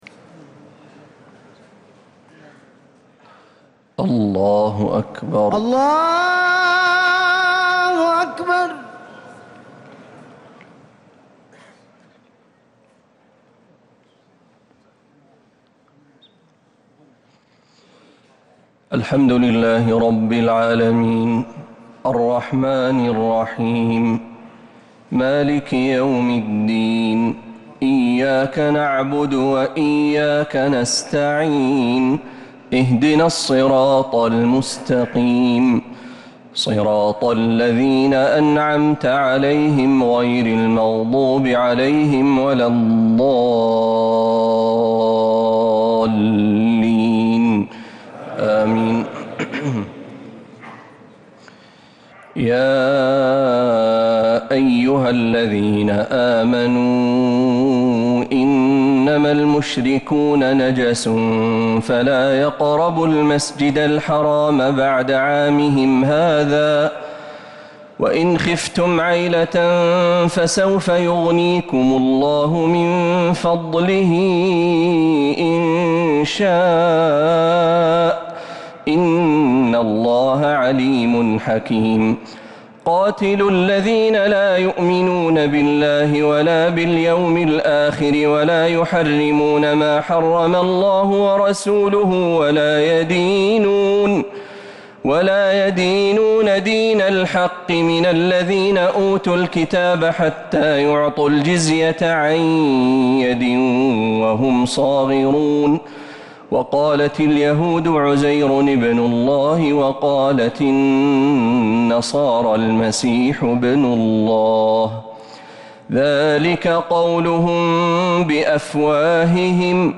تراويح ليلة 13 رمضان 1447هـ من سورة التوبة (28-60) | Taraweeh 13th niqht Surat At-Tawba 1447H > تراويح الحرم النبوي عام 1447 🕌 > التراويح - تلاوات الحرمين